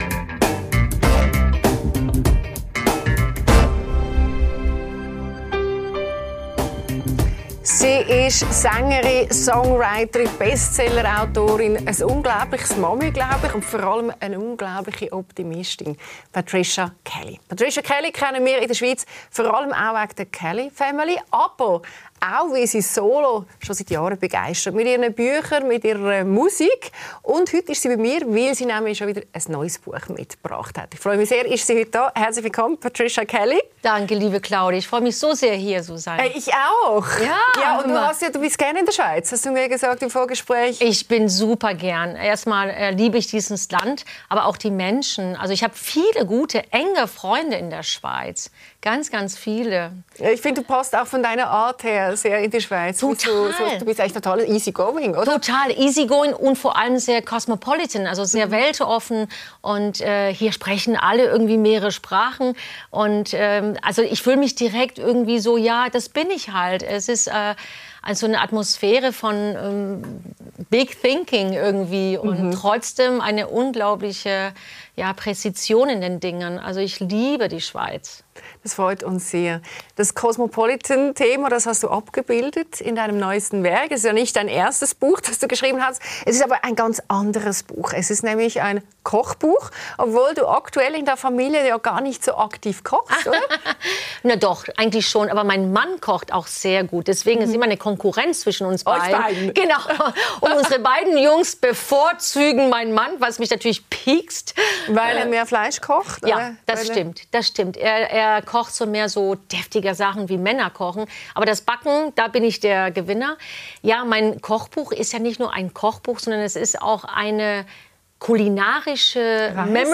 Nach schwerer Krankheit fand Patricia Kelly neue Stärke in Familie, Glaube und Musik. Im Gespräch spricht sie über Resilienz, kleine Rituale und ihr neues Kochbuch, das mehr ist als nur Rezepte.